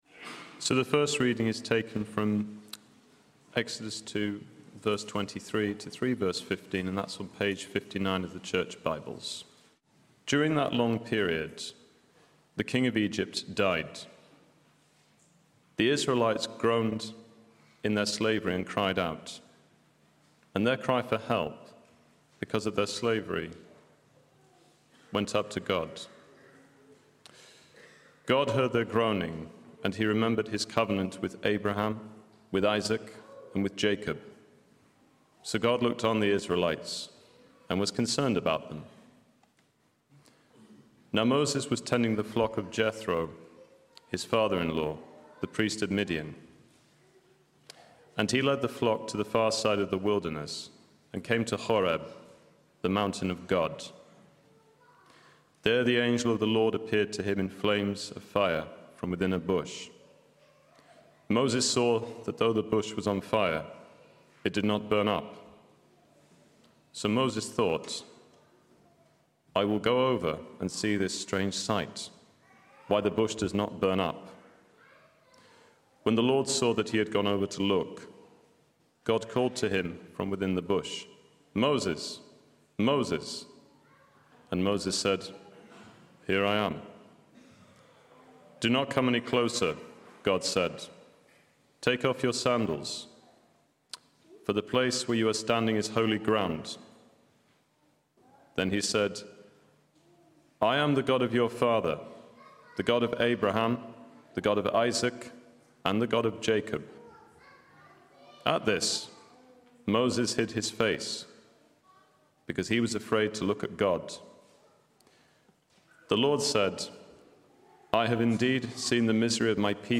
Sermons Archive - Page 15 of 187 - All Saints Preston